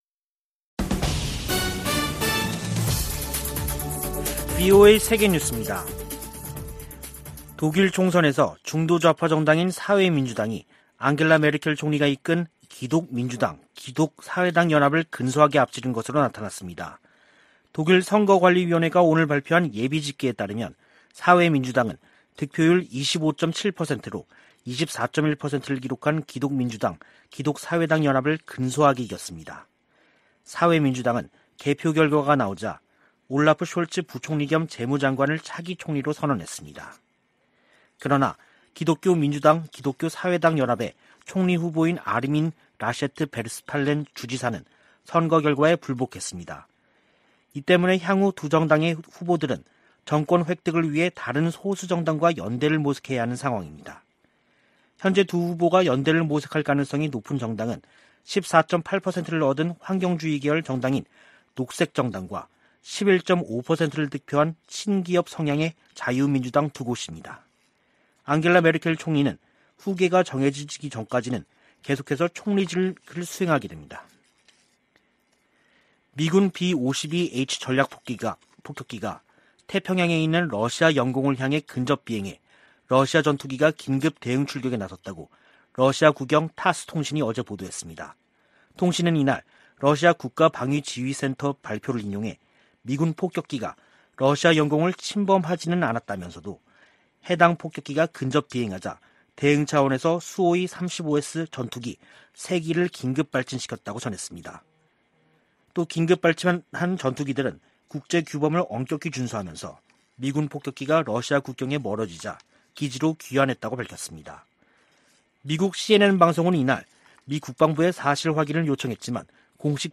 VOA 한국어 간판 뉴스 프로그램 '뉴스 투데이', 2021년 9월 27일 2부 방송입니다. 김여정 북한 노동당 중앙위원회 제1부부장이 연이틀 담화를 통해 유화적 메시지를 보내 주목됩니다. 북한은 제재 완화든 경제 발전이든 원하는 것이 있다면 협상장으로 나와야 한다고 미 국무부 부차관보가 지적했습니다. 국제원자력기구(IAEA)가 북한에 비핵화 이행을 촉구하고 국제사회의 외교적 노력을 지지하는 결의안을 채택했습니다.